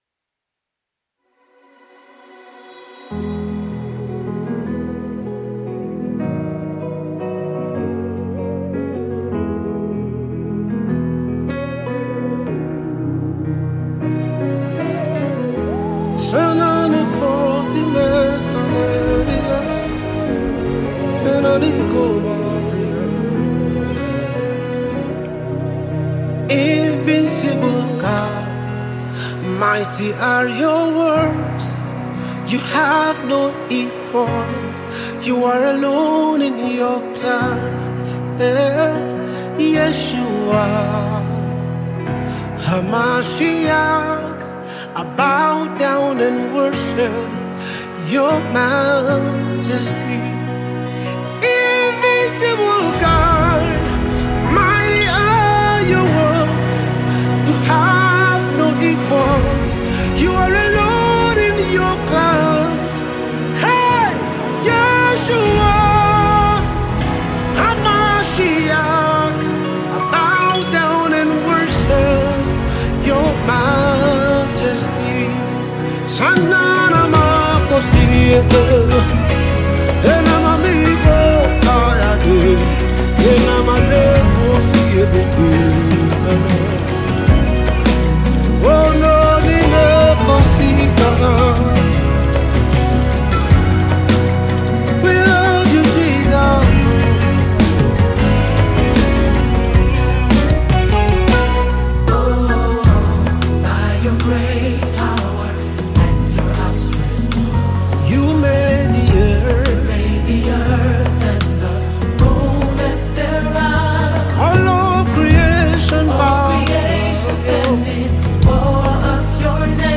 Renowned Nigerian gospel singer and songwriter